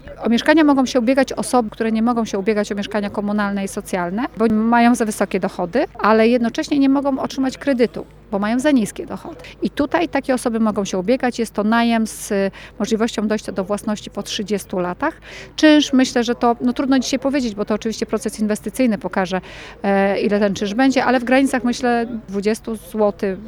Kto może ubiegać się o przydział? O tym burmistrz Pyrzyc, Marzena Podzińska: